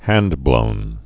(hăndblōn)